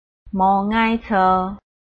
臺灣客語拼音學習網-客語聽讀拼-詔安腔-開尾韻
拼音查詢：【詔安腔】ngai ~請點選不同聲調拼音聽聽看!(例字漢字部分屬參考性質)